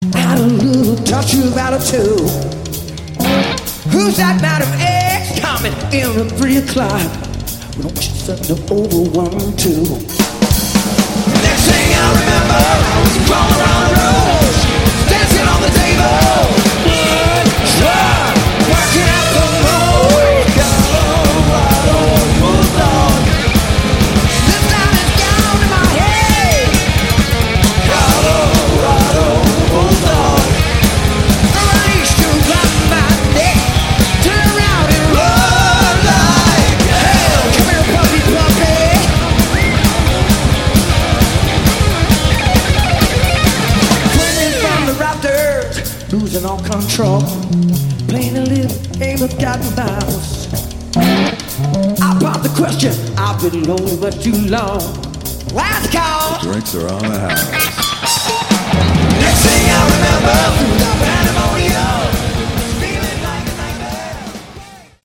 Category: Hard Rock
Bass
vocals
Drums
Guitars